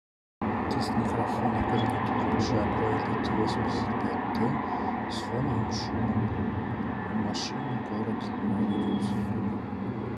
🎙 Микрофон
Сделал пару тестовых записей на диктофон. Надо сказать, вариант с шумом города с YouTube оказался на удивление плохим. Уточню, что колонки стояли рядом, а шум был громким (его же использовал для тестирования шумоподавления).
Jabra Elite 85t микрофон в тихой обстановке Jabra Elite 85t микрофон в шумной обстановке 🔊 Качество звука Звук Jarba Elite 85t мне понравился.